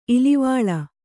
♪ ilivāḷa